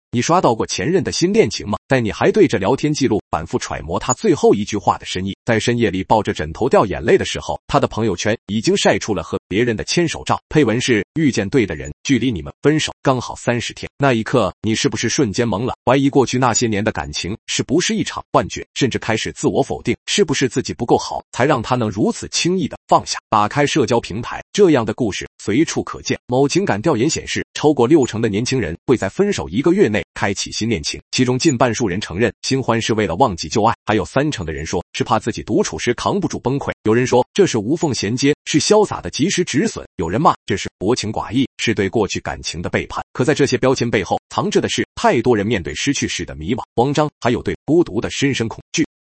效果演示(不做任何后期处理！所听即所得，语速可调)
复刻后：
声音复刻采用阿里云大模型，复刻真实度非常高